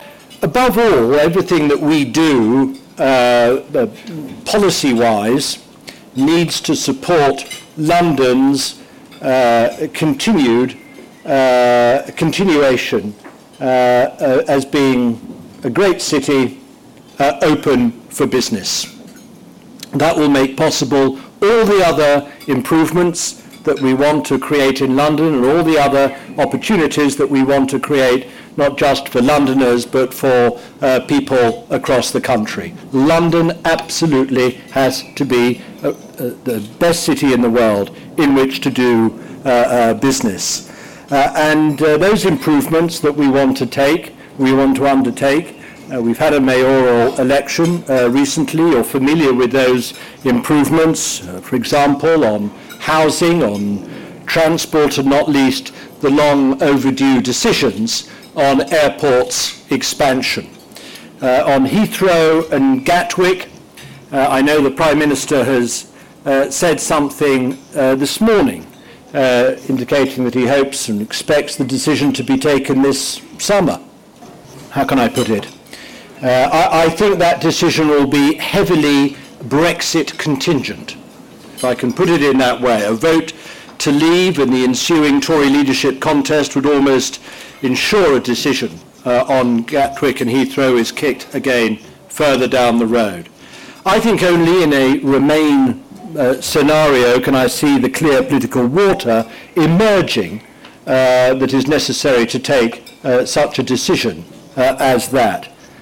At our London Summer Business Lunch, Lord Mandelson keynote speaker, Lord Mandelson, former European Commissioner for Trade and Secretary of State for Business, talked about how Brexit could influence the Government's decision on airport expansion.